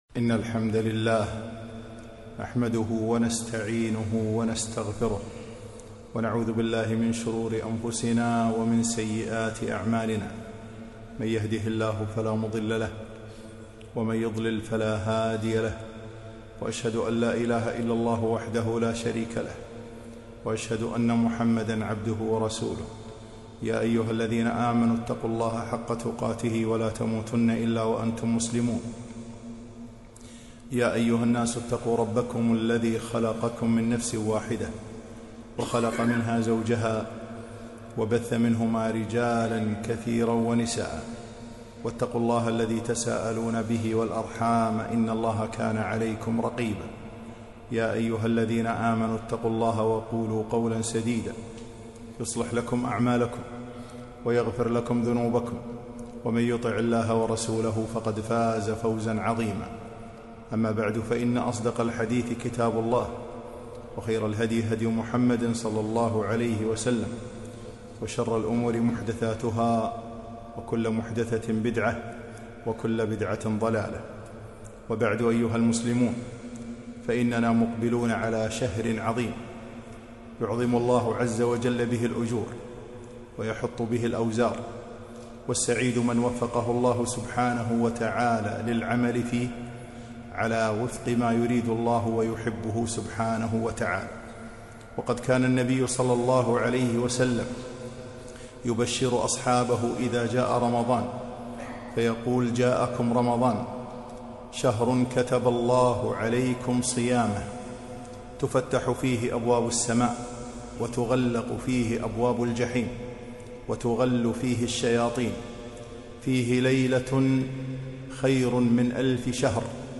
خطبة - أقبلت يا رمضان